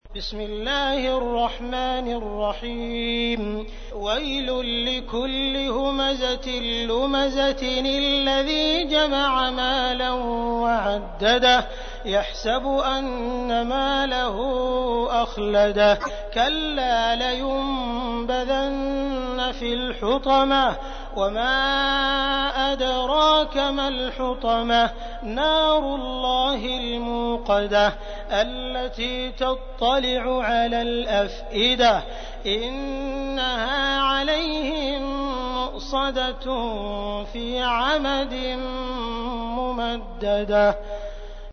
تحميل : 104. سورة الهمزة / القارئ عبد الرحمن السديس / القرآن الكريم / موقع يا حسين